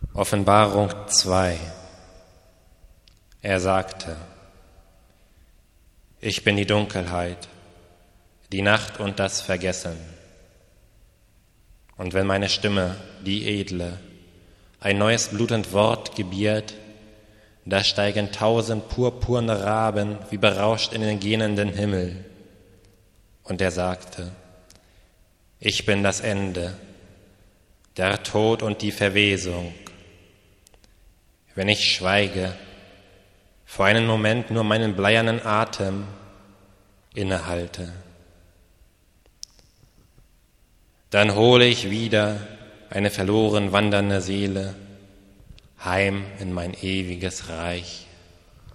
Hier gibt es einige improvisierte Lesungen meiner Texte zum Herunterladen im MP3-Format, in seltenen Fällen sind sie musikalisch untermalt. Es handelt sich um Lo-Fi Produktionen, wenn man sie überhaupt Produktionen nennen mag.